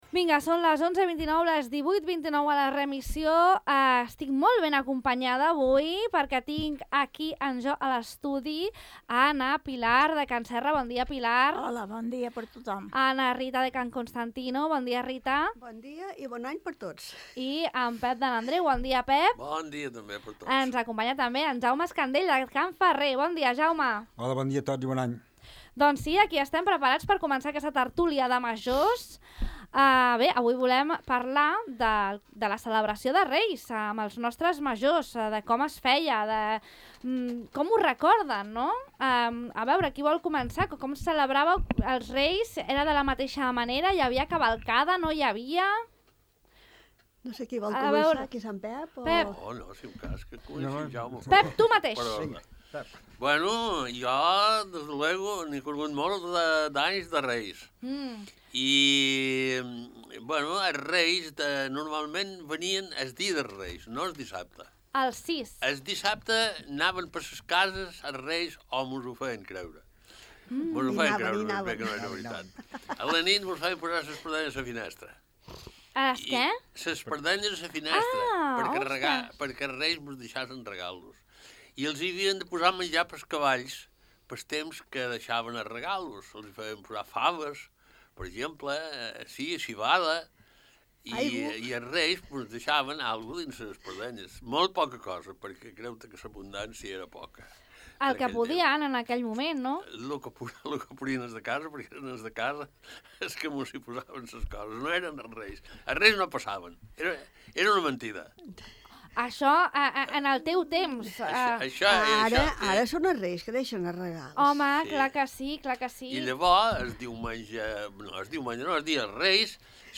La festa de Reis, a la Tertúlia de Majors